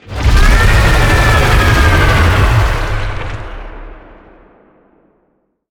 Sfx_creature_iceworm_roar_03.ogg